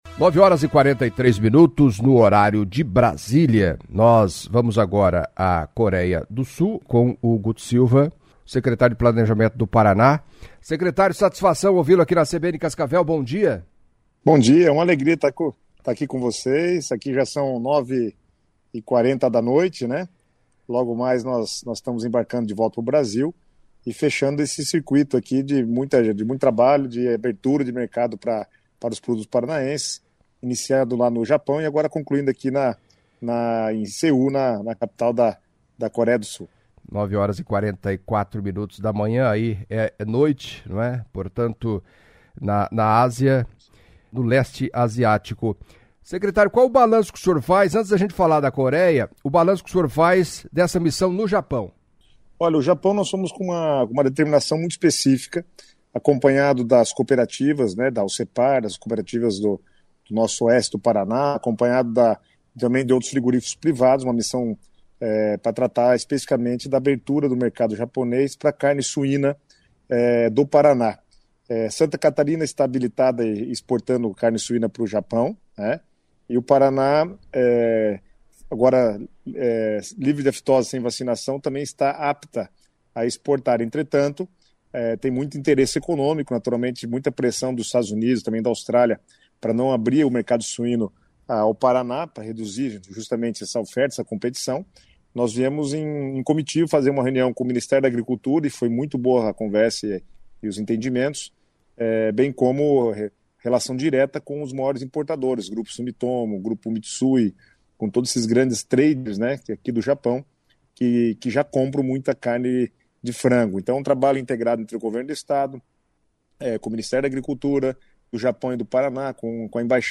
Em entrevista à CBN Cascavel nesta quarta-feira (15) o secretário de estado do Planejamento, Guto Silva, destacou a importância da presença da comitiva do governador Ratinho Júnior ao leste asiático. A missão, inicialmente no Japão e depois na Coreia do Sul, teve início no dia 04 de março e encerra nesta quarta-feira (15), tendo como meta expandir as exportações paranaenseses para esses dois importantes mercados asiáticos.